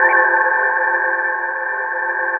Index of /90_sSampleCDs/Best Service ProSamples vol.36 - Chillout [AIFF, EXS24, HALion, WAV] 1CD/PS-36 AIFF Chillout/AIFF Synth Atmos 2